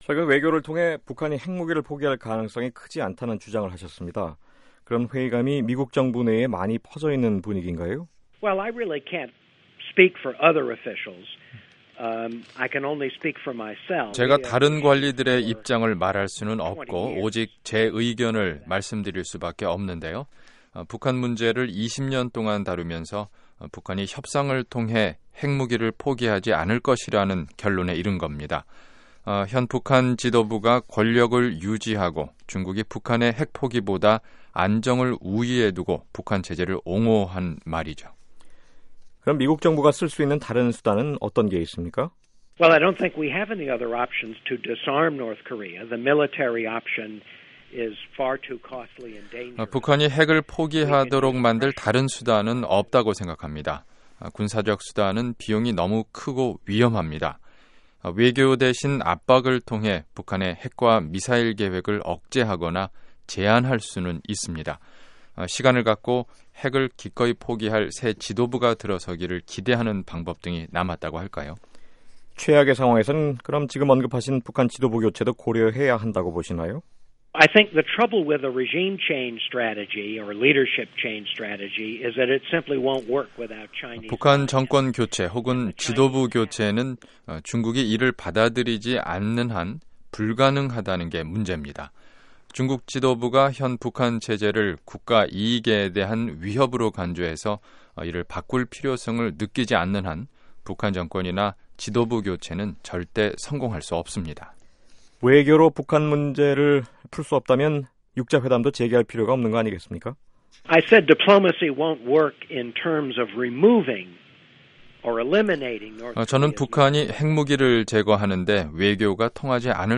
[인터뷰] 세이모어 전 백악관 조정관 "중국, 김정은에 불만 팽배"